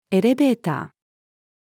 エレベーター-female.mp3